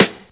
• 00s Dry Hip-Hop Snare Drum Sample G# Key 24.wav
Royality free steel snare drum tuned to the G# note. Loudest frequency: 1977Hz
00s-dry-hip-hop-snare-drum-sample-g-sharp-key-24-RSp.wav